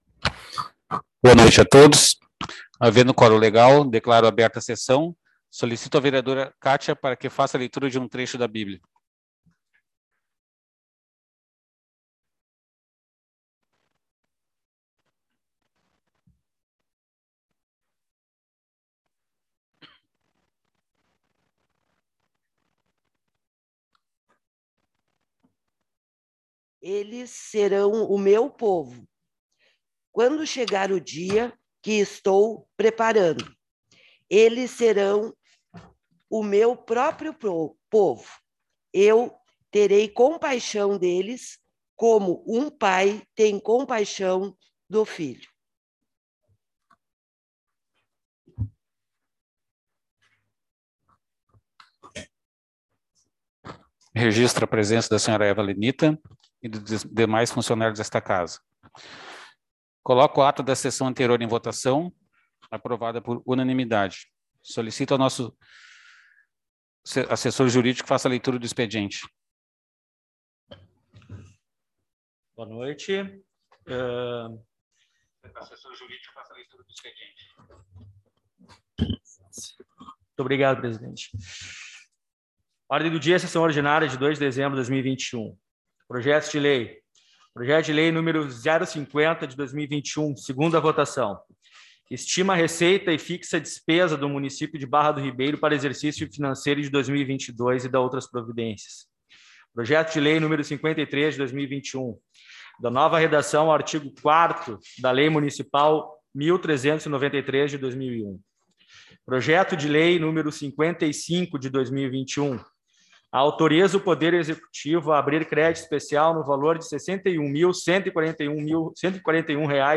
Sessão 02.12.2021